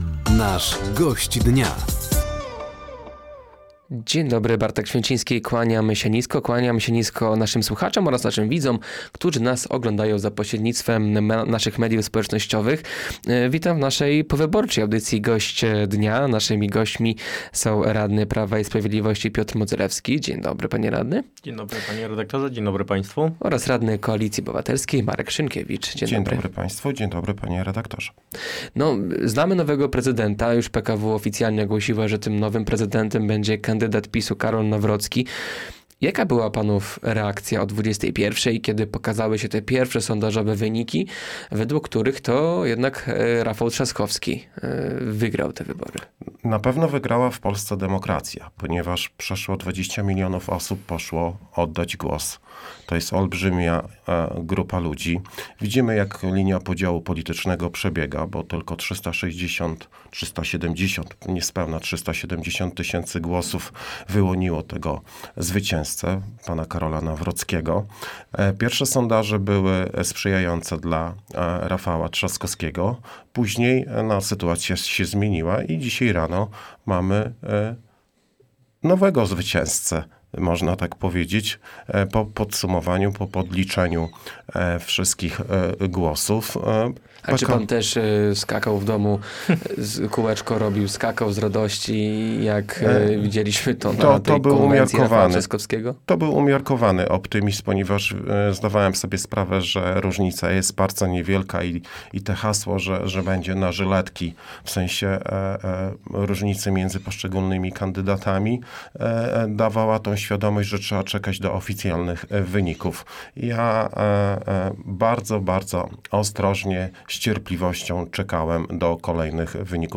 Gośćmi Dnia Radia Nadzieja byli radny PiS Piotr Modzelewski oraz radny KO Marek Szynkiewicz. Tematem rozmowy była wygrana Karola Nawrockiego w wyborach prezydenckich.